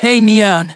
synthetic-wakewords
ovos-tts-plugin-deepponies_Adachi Tohru_en.wav